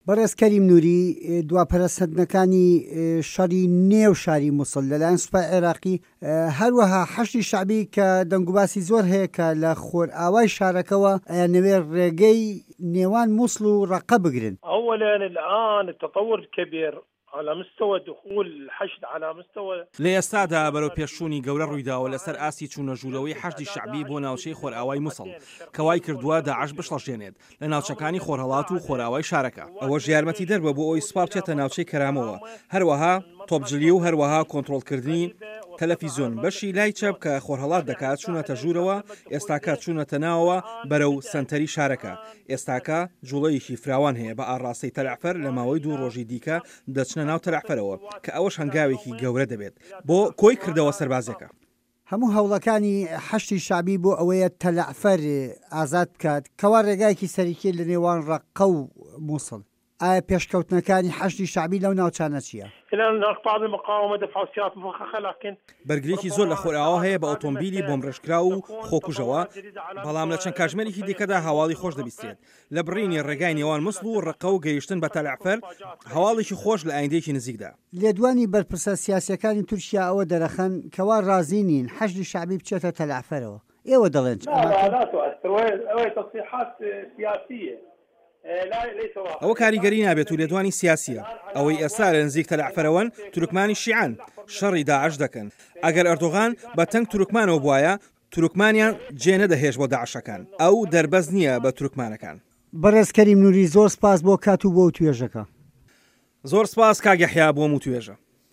ئه‌و به‌رپرسه‌ی حه‌شد که‌ له‌ شوێنێکی پڕ له‌ ژاوه‌ژاو وتوێژی له‌گه‌ڵ ده‌کرا باسی له‌ پرۆسه‌ی گرتنه‌وه‌ی ته‌له‌عفه‌ر ده‌کرد که‌ به‌م نزیکانه‌ هه‌واڵی خۆش راده‌گه‌یه‌نن، وتیشی "هه‌رچه‌نده‌ هێزه‌کانمان روبه‌ڕووی چه‌ندین هێرشی خۆکوژی و ئوتومبیلی بۆمب رێژ کراو بوونه‌ته‌وه‌، به‌ڵام ئێمه‌ ئێستا نزیکین له‌ ته‌له‌عفه‌ره‌وه‌."
وتووێژ